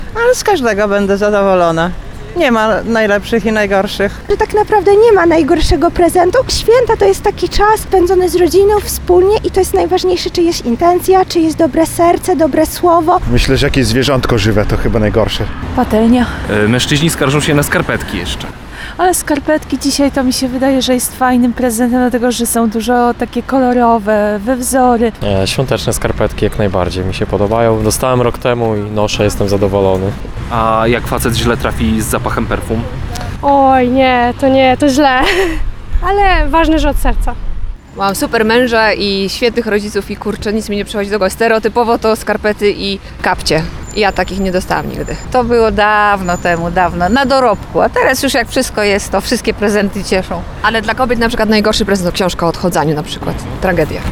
Skarpety, dezodorant albo niemodny krawat – są na liście rzeczy, których wolelibyśmy nie dostać. O to, co zrobić z nietrafionym prezentem, zapytaliśmy suwalczan. Najważniejsze to się nie martwić.